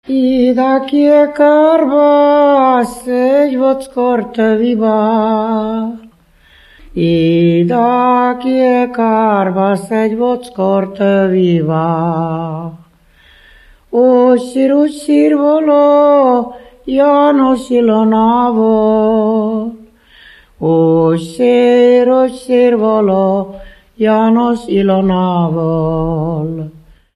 Moldva és Bukovina - Moldva - Trunk
Műfaj: Ballada
Stílus: 3. Pszalmodizáló stílusú dallamok
Szótagszám: 6.6.6.6
Kadencia: 7 (4) b3